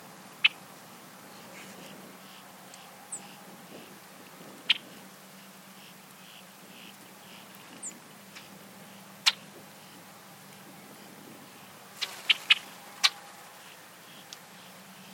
Red-winged Blackbird